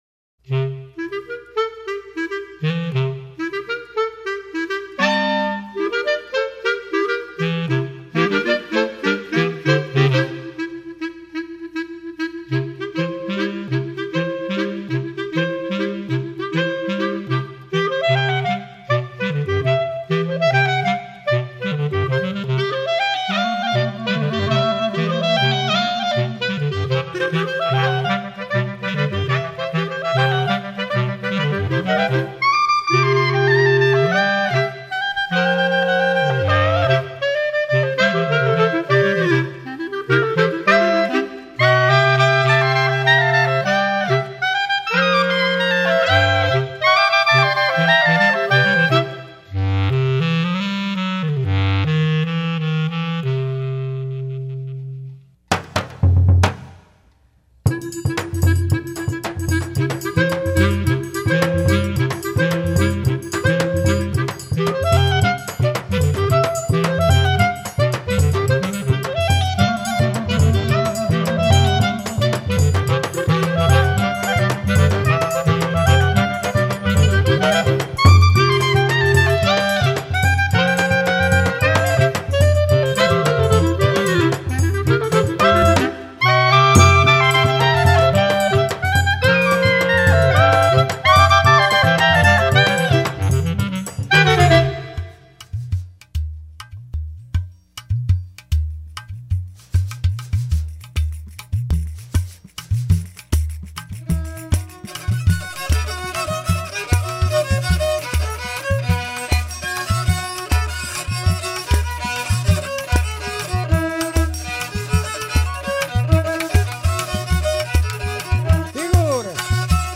2012   05:41:00   Faixa:     Instrumental